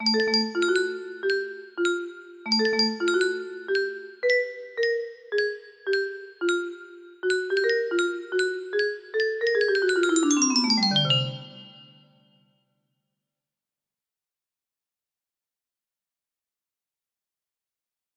Dum dum music box melody